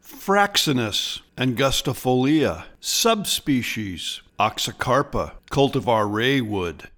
Pronounciation:
FRAX-in-us an-gus-ti-fol-EE-a ox-ee-CAR-pa RAY-wood